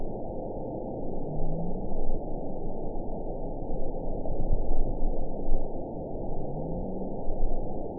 event 910148 date 01/16/22 time 06:36:55 GMT (3 years, 10 months ago) score 8.52 location TSS-AB07 detected by nrw target species NRW annotations +NRW Spectrogram: Frequency (kHz) vs. Time (s) audio not available .wav